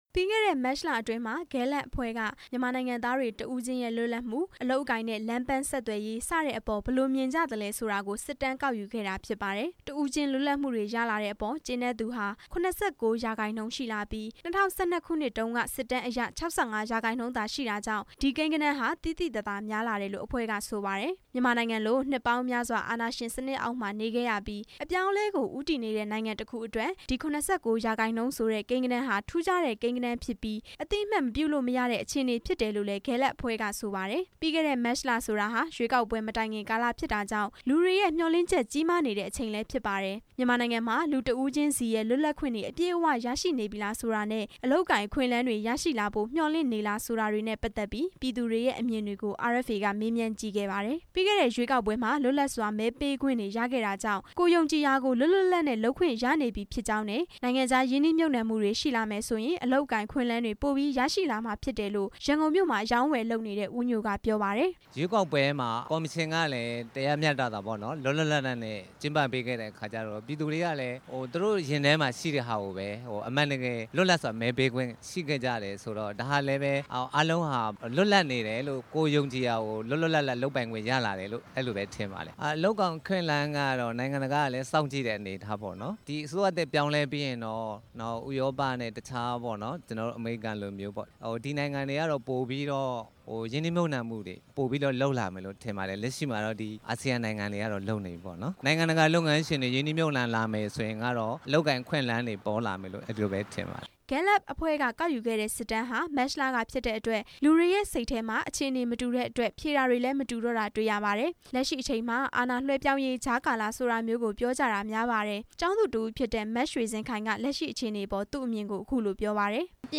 အဲ့ဒီ လေ့လာခဲ့တဲ့ စစ်တမ်းအပေါ် ရန်ကုန်မြို့မှာ အလုပ်လုပ်နေကြသူတွေရဲ့ အမြင်တွေကို မေးမြန်းပြီး